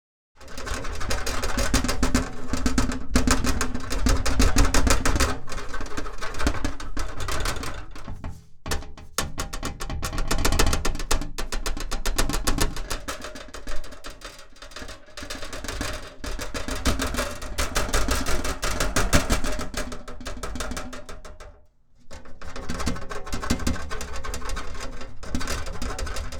Metal-rumbling-rattling-and-vibrating-designed.mp3